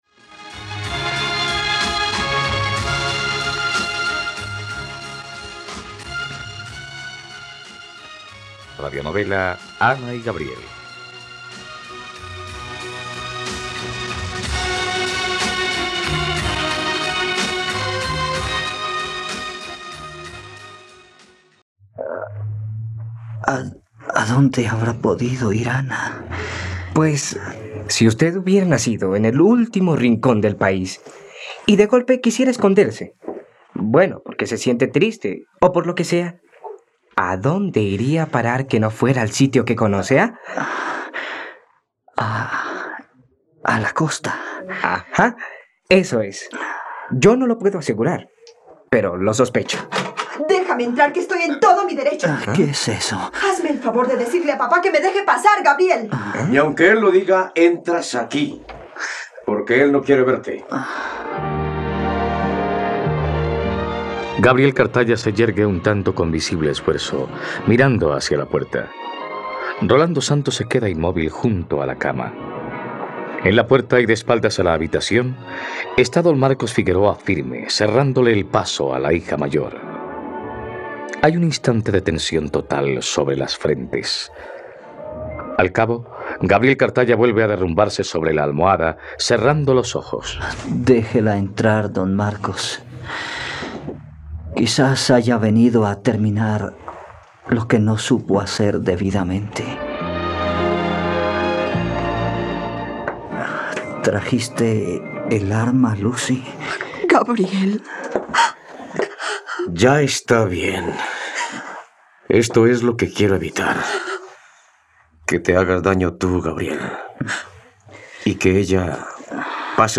..Radionovela. Escucha ahora el capítulo 106 de la historia de amor de Ana y Gabriel en la plataforma de streaming de los colombianos: RTVCPlay.